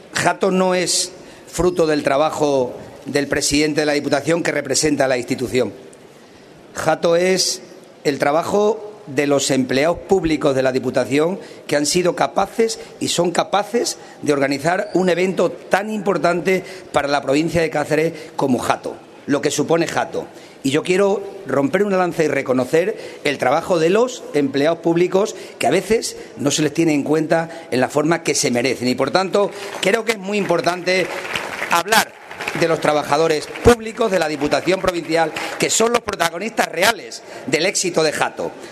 Y en la misma línea argumental, durante su intervención desde el Foro de los Balbos, el presidente de la Diputación de Cáceres, Miguel Ángel Morales, ha aseverado que JATO viene a representar lo que somos y lo que ofrecemos: “JATO es el grito del orgullo de sentirse de pueblo y del mundo rural, de saber que esta provincia no está despoblada ni vaciada, que es una provincia llena de gente con ilusión, esperanza y proyectos, y que se puede hacer una vida arriesgando y las instituciones tenemos la obligación de facilitar a los emprendadores, a las empresas, que creen trabajo y que, por tanto, hagan viables los pueblos de la provincia”.
MIguel-A-Morales_Reconocimiento-empleados-publicos.mp3